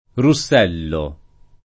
[ ru SS$ llo ]